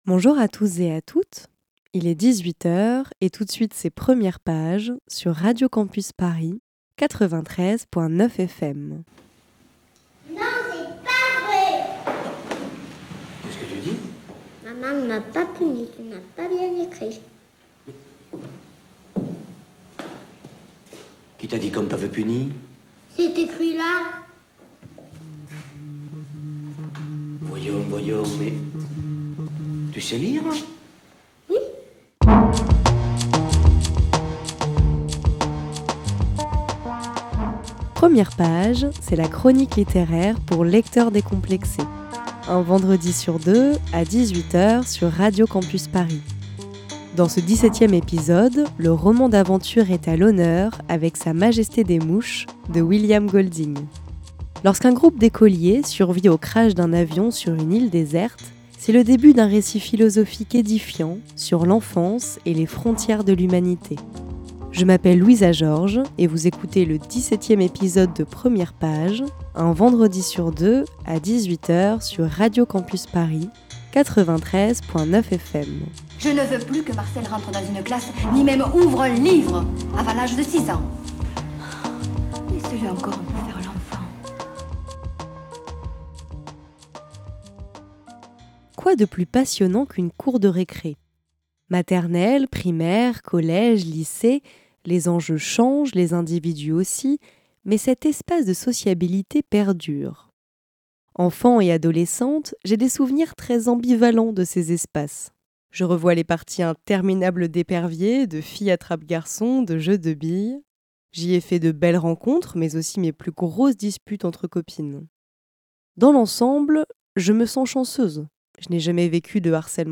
Voici en podcast le dix-septième épisode de Premières pages, une chronique littéraire pour lecteur.trice.s decomplexé.e.s, un vendredi sur deux sur Radio Campus Paris.